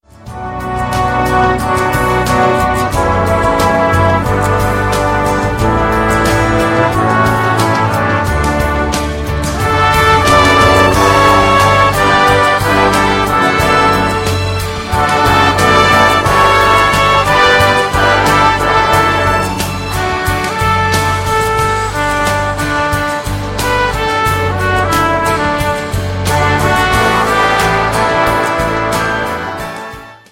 • Sachgebiet: Instrumental